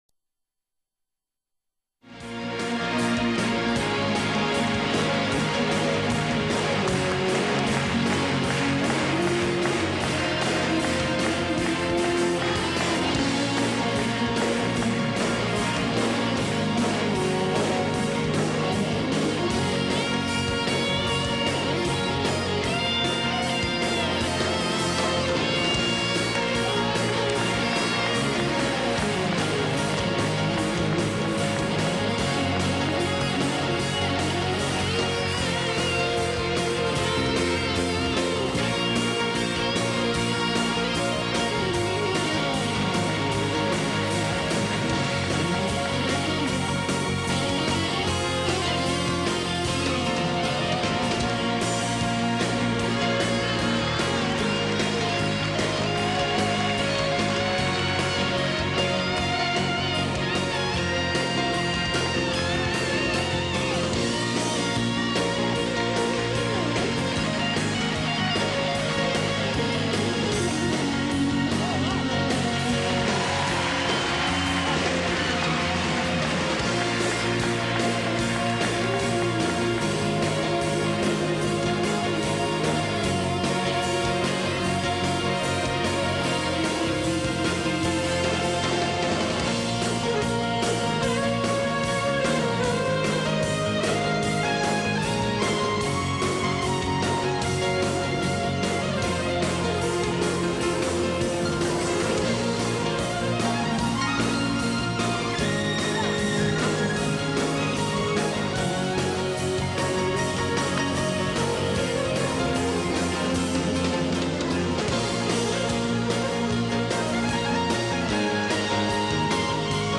2012-11-28 오후 5:29:00 안녕하세요 우연히 듣게 된 곡인데 음악 스타일이 8~90년대 더스퀘어랑 상당히 유사합니다.